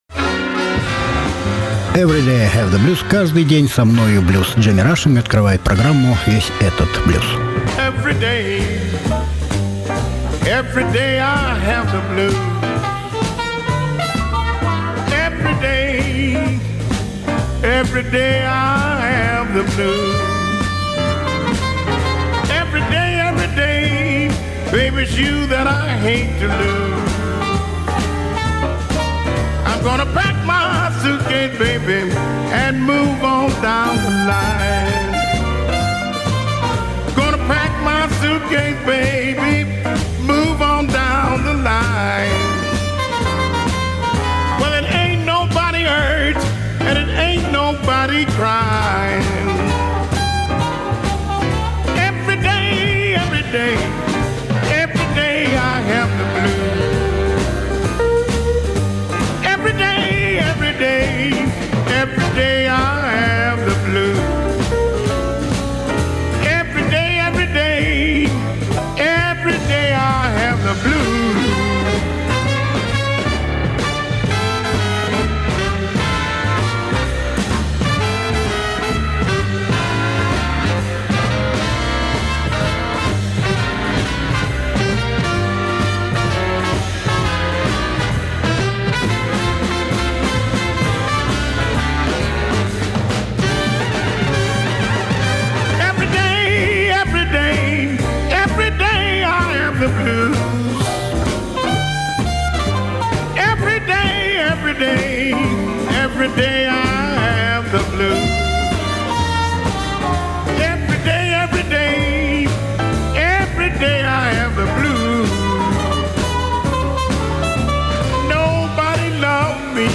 Американский джазовый, блюзовый певец